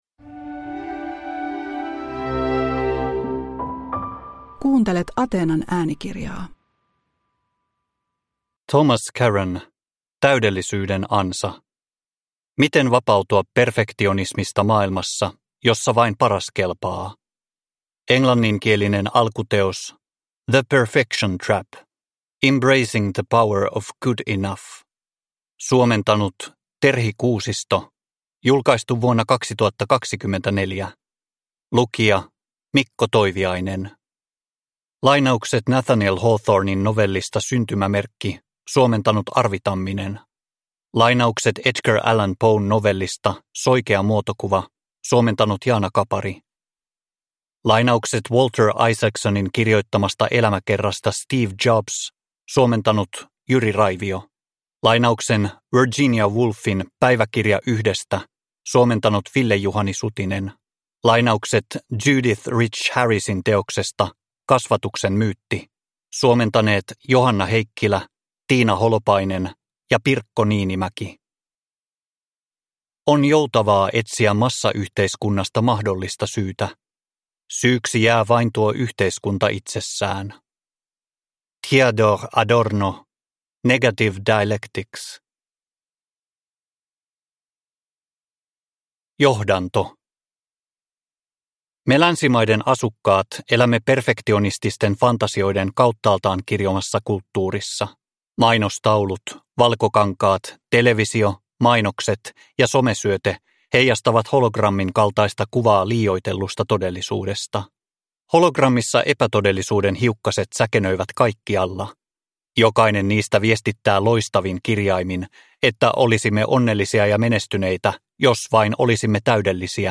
Täydellisyyden ansa (ljudbok) av Thomas Curran